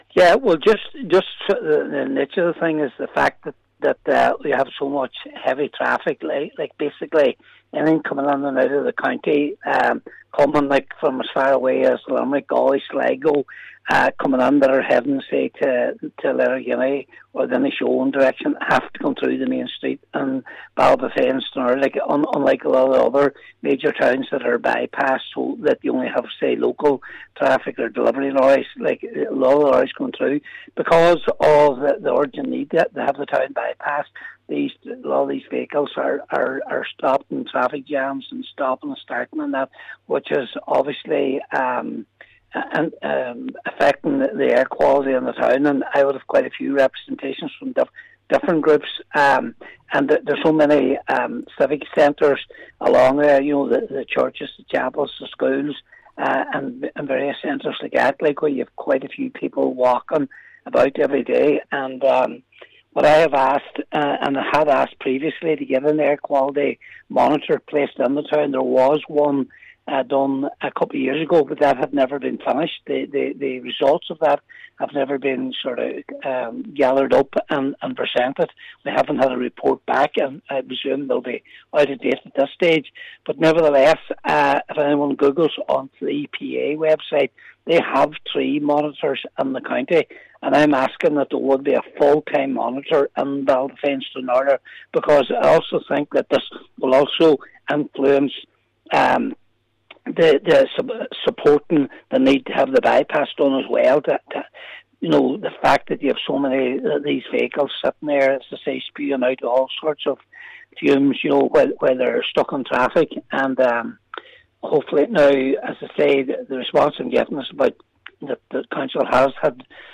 Cllr McGowan says this must be highlighted in a bid to expedite the Twin Town by pass: